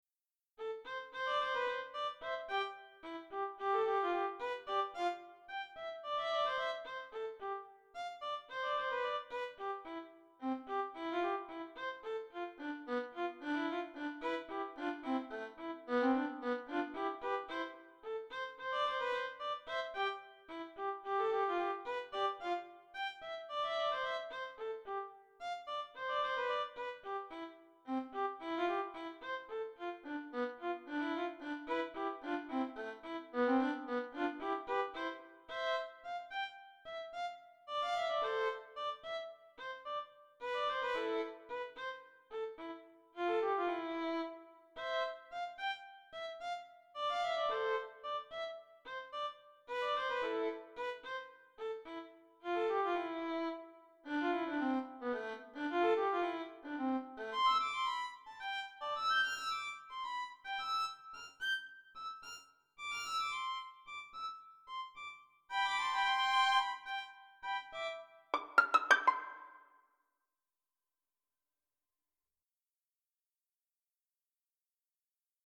Caprice for Solo Violin No. 6